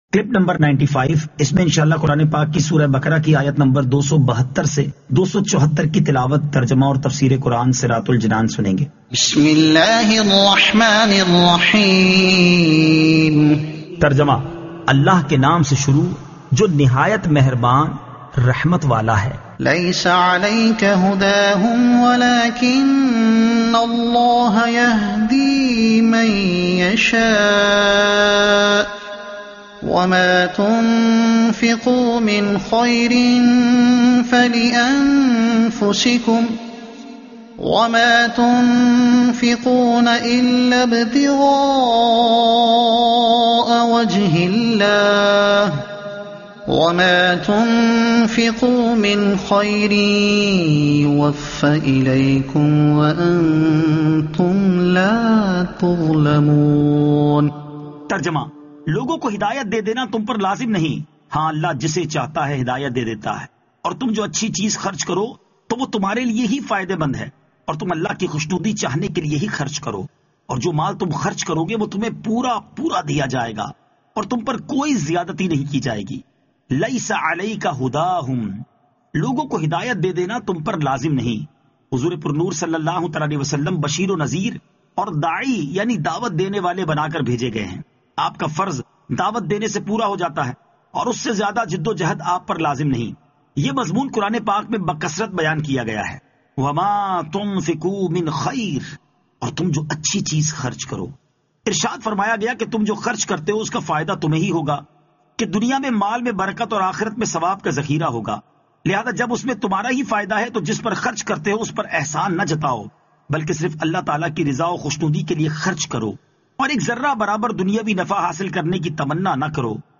Surah Al-Baqara Ayat 272 To 274 Tilawat , Tarjuma , Tafseer